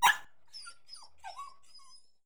Bark4.wav